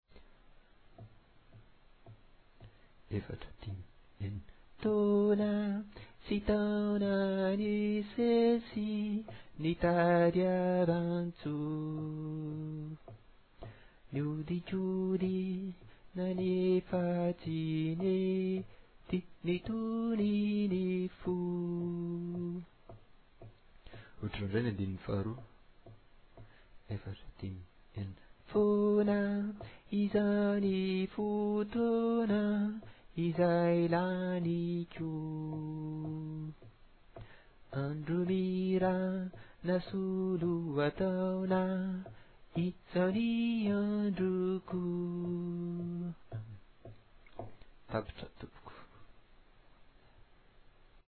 fianarana Solfa